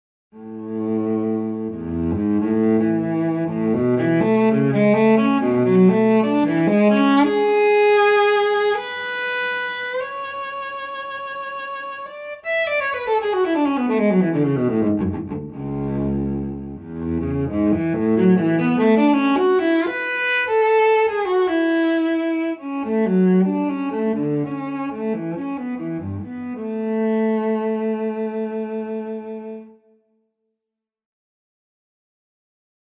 I like how it reacts to pitch info... that's the way I do vibrato with this cello, as I can inject some life in every note.
No, no eq applied, this was the sound of the cello as it is, with some lexi plate added...
SwamCello-pitchVibrato.mp3